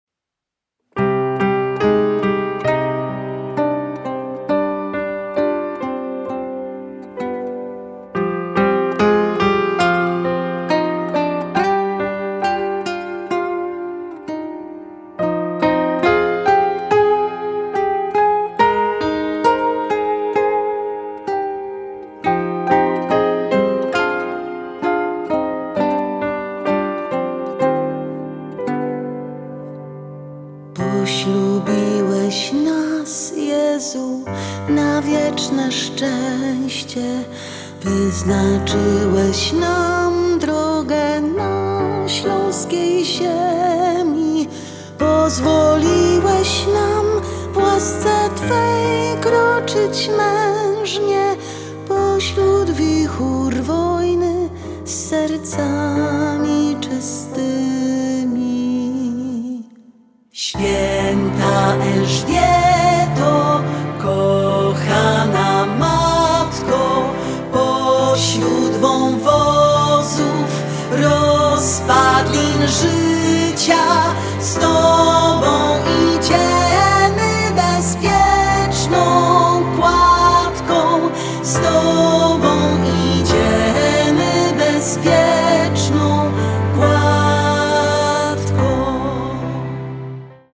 ŚPIEW I CHÓRKI:
SKRZYPCE:
FLET:
GITARY, INSTRUMENTY OERKUSYJNE: